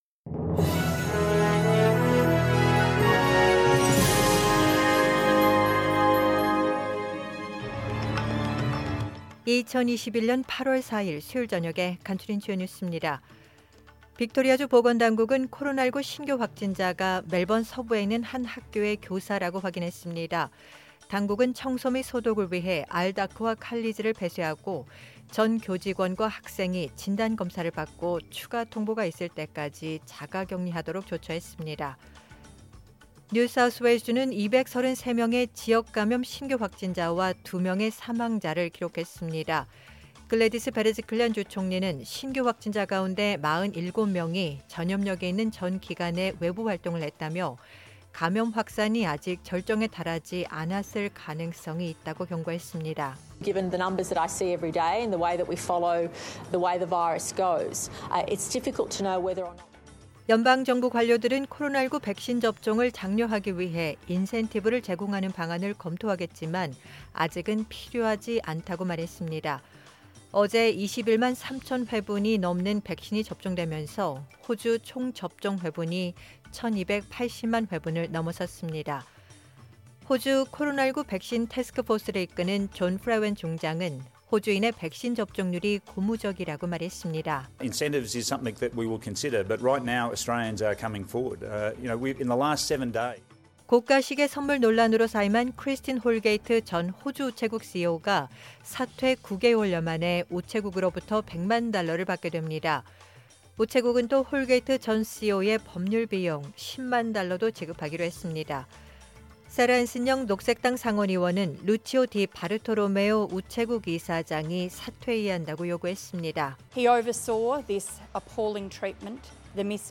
2021년 8월 4일 수요일 저녁의 SBS 뉴스 아우트라인입니다.